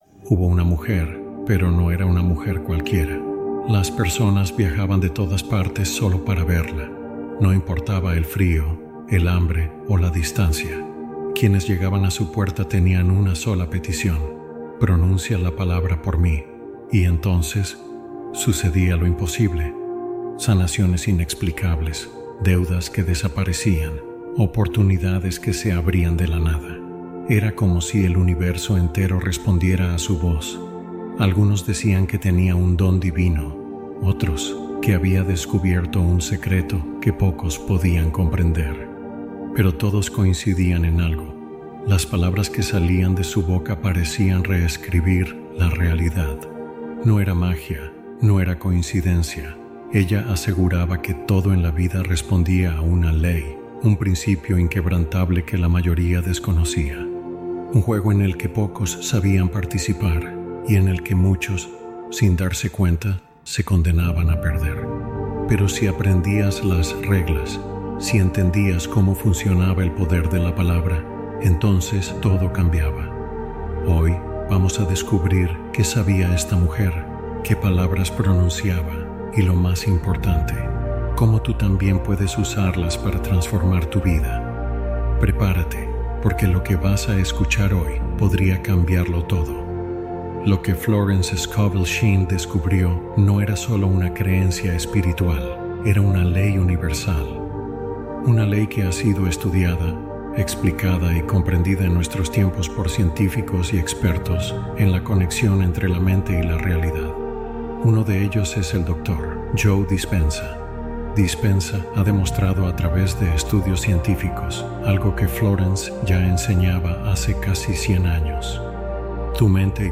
Esta Palabra Ancestral Produce Milagros | Meditación Profunda